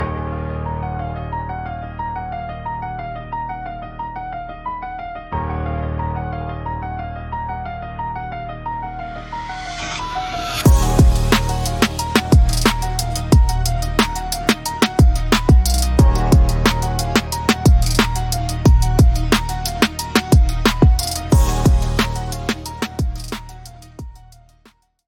Style : Rap
Instrumental